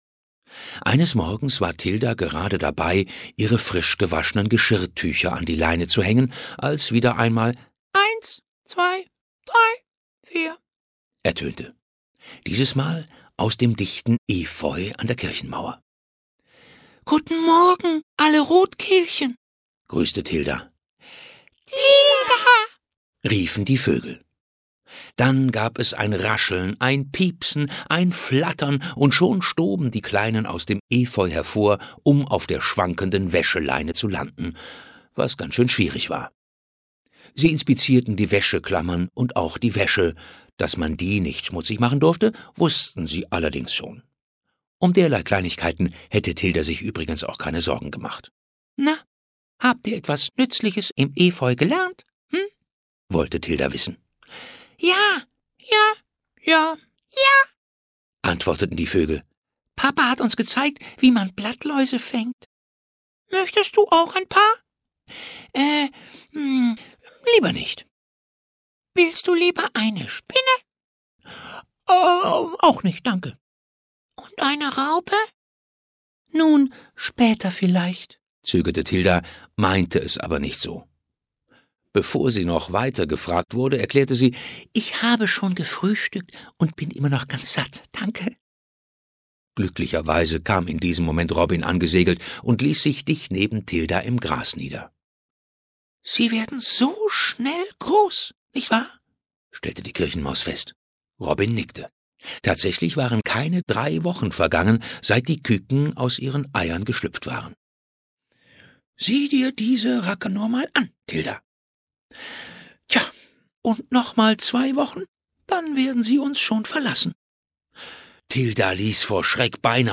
Ravensburger Tilda Apfelkern - Frühling, Sommer, Herbst und Winter - ein schönes Jahr im Heckerosenweg ✔ tiptoi® Hörbuch ab 3 Jahren ✔ Jetzt online herunterladen!